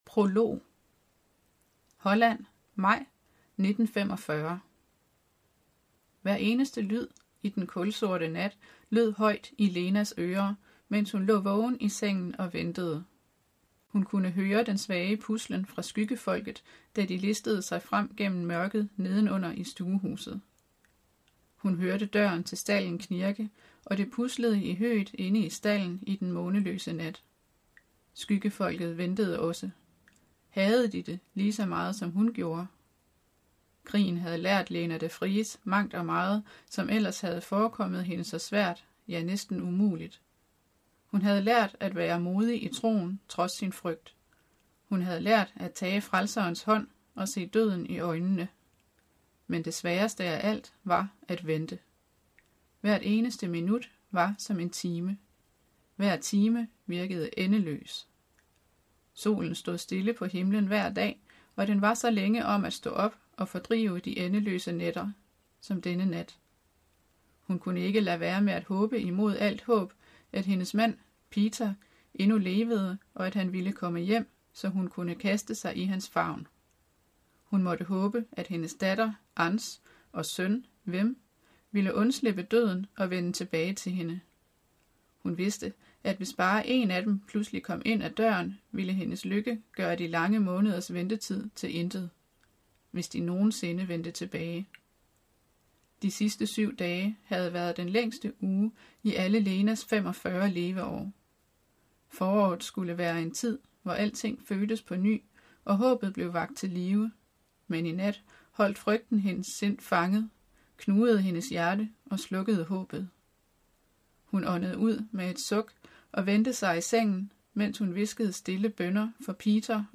Hør et uddrag af Skyggejagt Skyggejagt Format MP3 Forfatter Lynn Austin Bog Lydbog E-bog 249,95 kr.